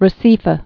(rə-sēfə)